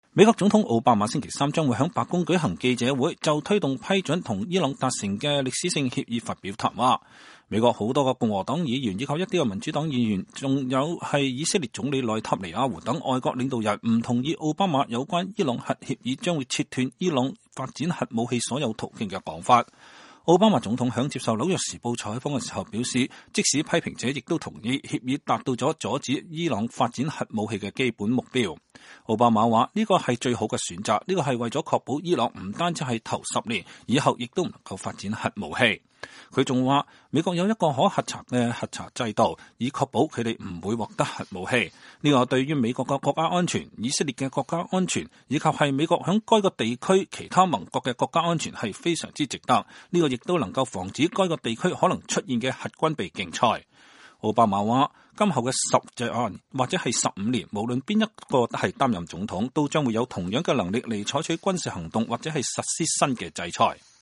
美國總統奧巴馬星期二就伊朗核協議發表講話。